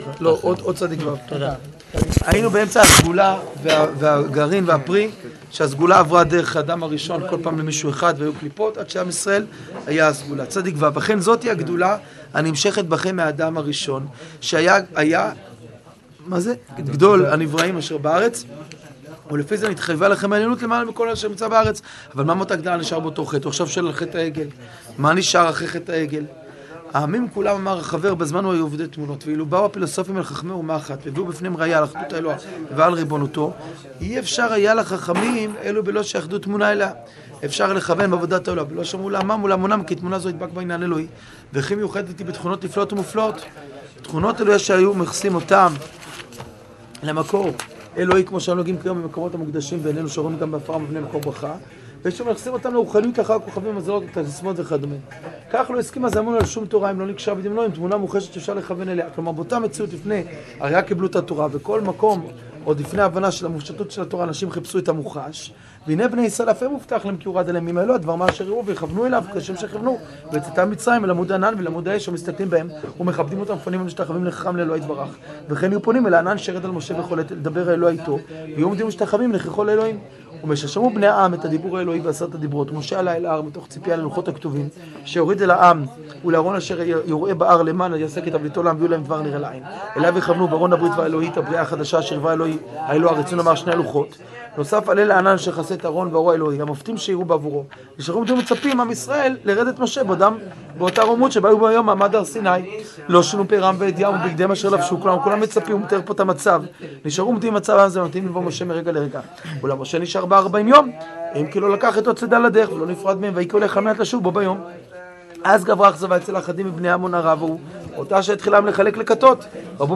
פסקות צו-צז מכוחות הטבע שיעור קטוע